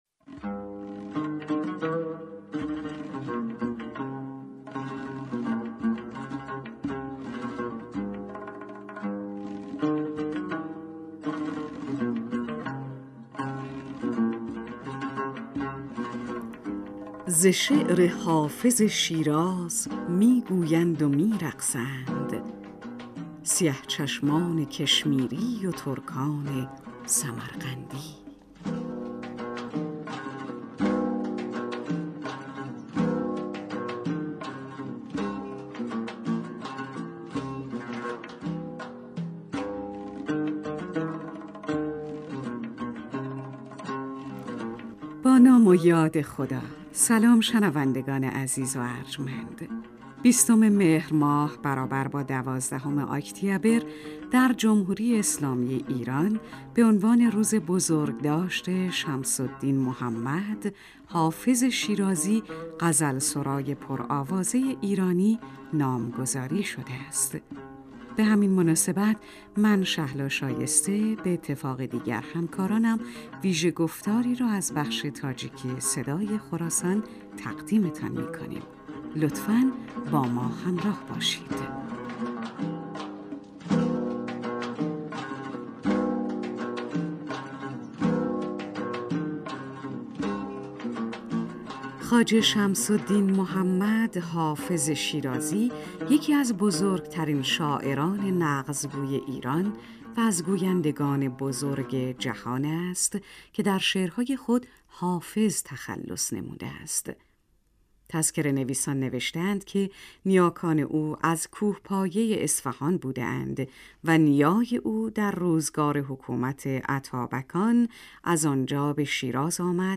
Ба муносибати рӯзи бузургдошти Ҳофизи Шерозӣ, шоири бузурги порсигуй, вижа барномае дар радиои тоҷикӣ таҳия шудааст, ки мешунавед :